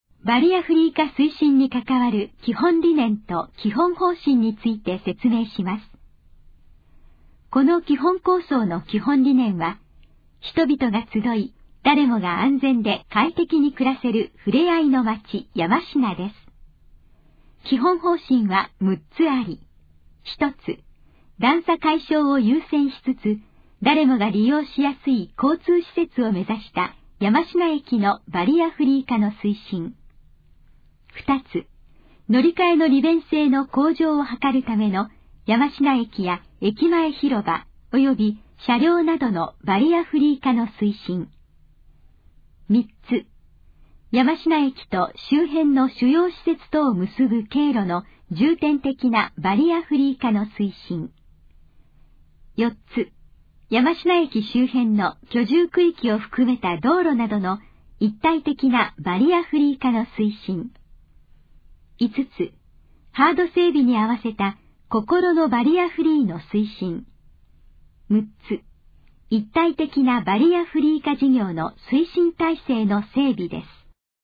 このページの要約を音声で読み上げます。
ナレーション再生 約149KB